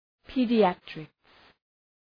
Προφορά
{,pi:dı’ætrıks} (Ουσιαστικό) ● παιδιατρική